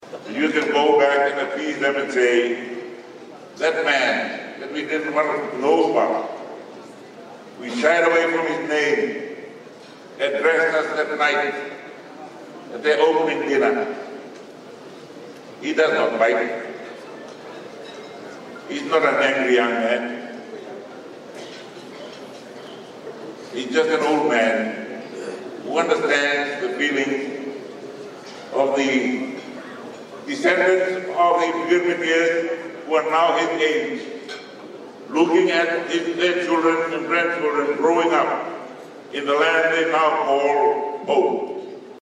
While speaking at the welcome dinner for the 12th World Hindi Conference in Nadi last night, Rabuka delivered a powerful assurance that the Girmitiya descendants who have made a life in Fiji belong to the tiny nation in the Pacific.